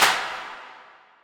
CC - Whip Clap.wav